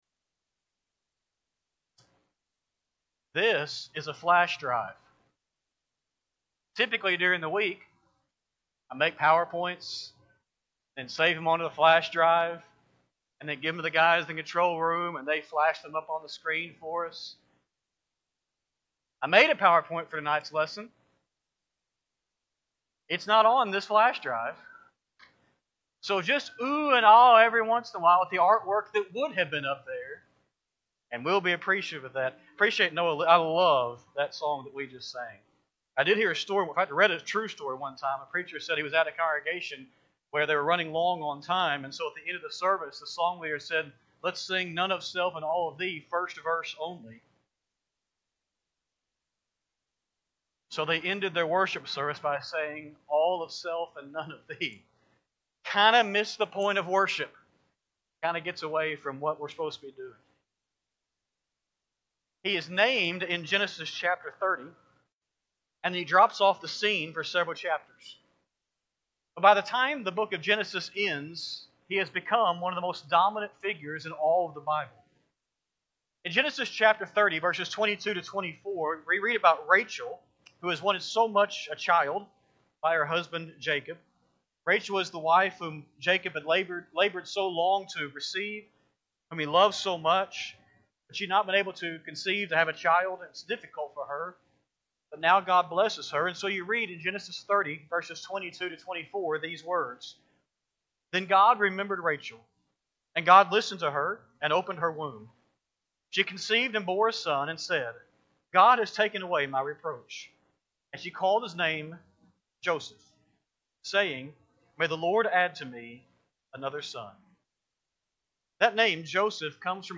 Sunday-PM-Sermon-1-4-26-Audio.mp3